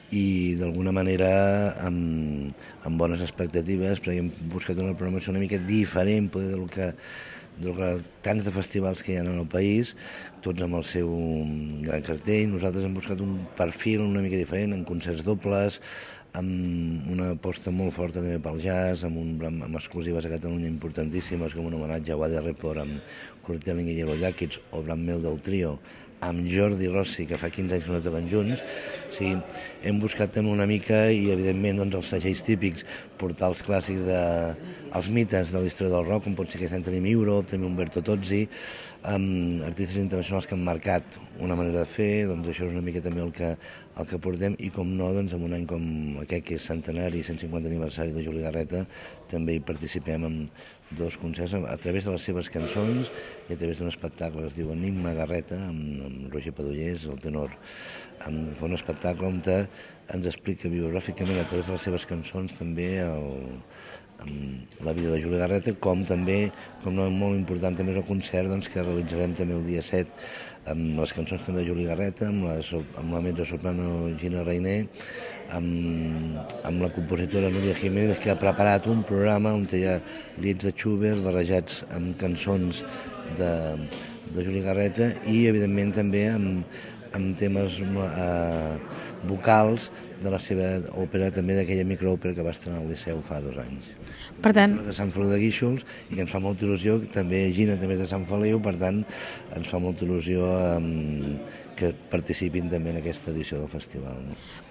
ha estat entrevistat